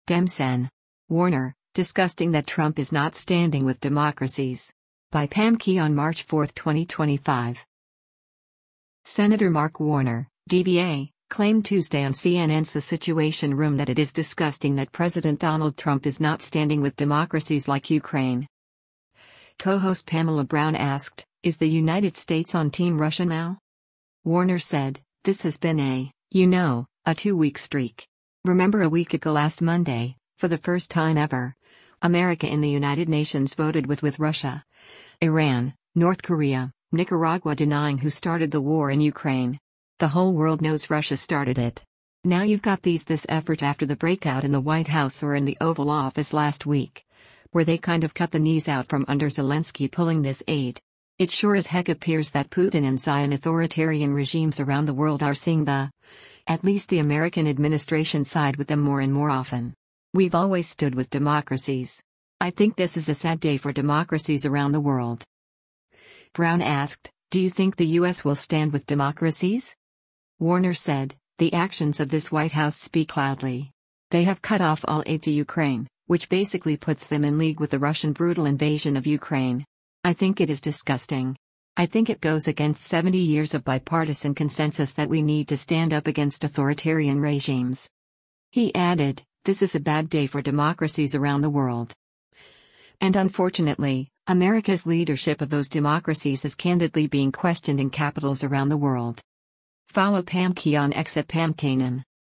Senator Mark Warner (D-VA) claimed Tuesday on CNN’s “The Situation Room” that it is “disgusting” that President Donald Trump is not standing with democracies like Ukraine.